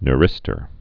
(n-rĭstər, ny-)